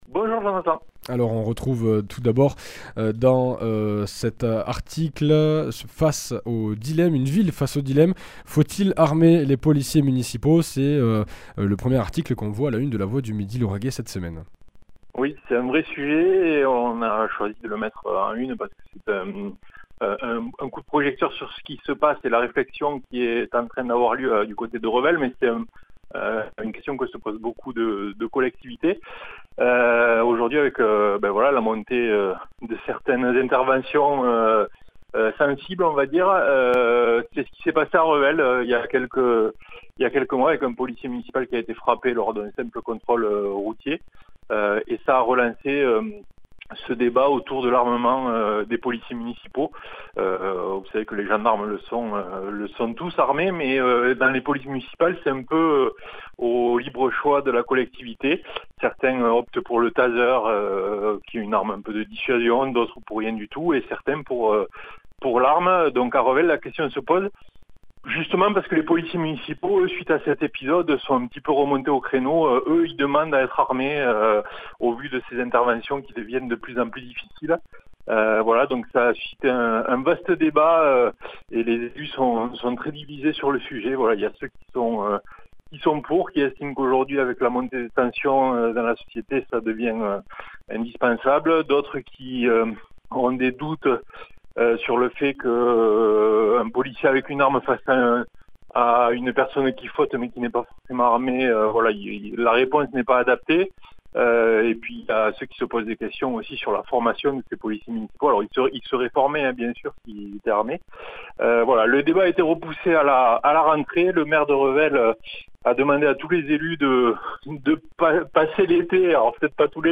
Animateur